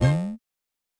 Buzz Error (12).wav